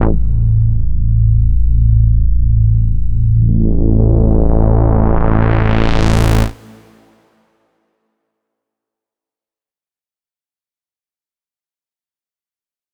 Bass_A_01.wav